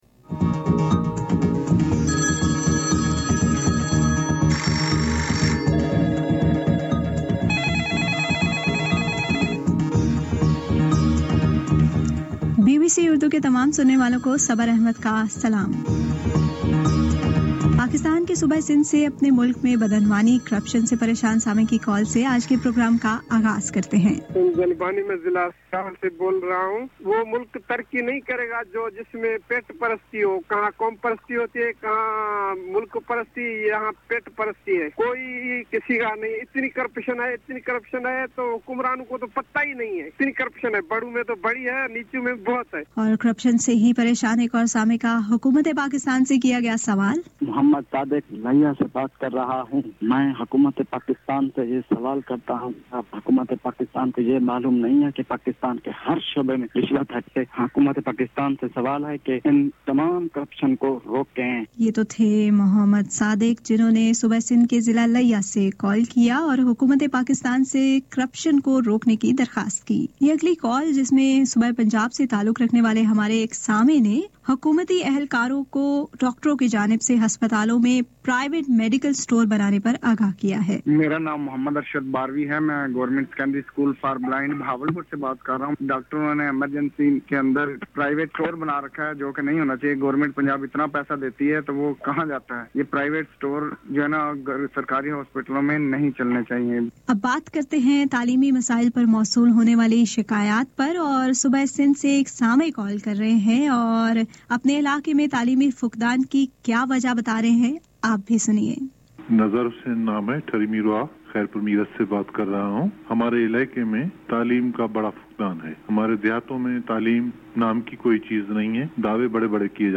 جہنوں نے صوبہ سندھ کے ضلع ، لیہ سے کال کیا اور حکومت ِپاکستان سے // کرپیشن کو روکنے کی درخواست کی۔